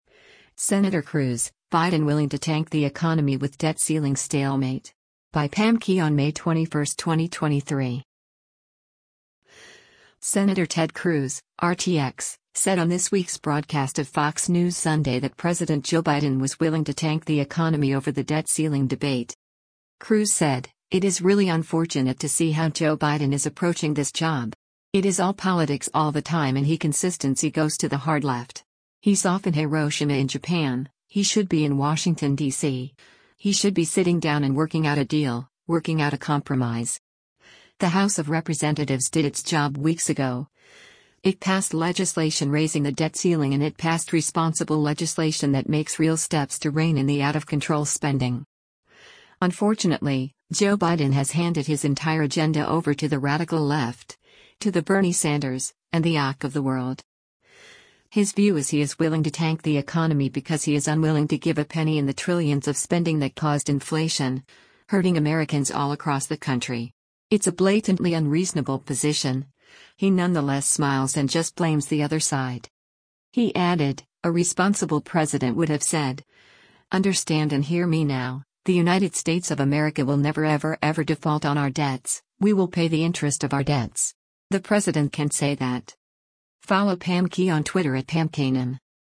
Senator Ted Cruz (R-TX) said on this week’s broadcast of “Fox News Sunday” that President Joe Biden was “willing to tank the economy” over the debt ceiling debate.